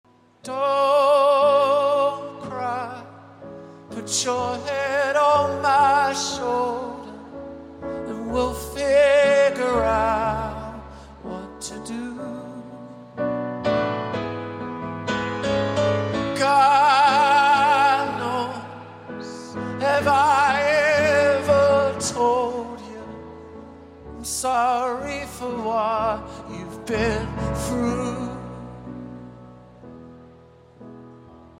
Live in Sacramento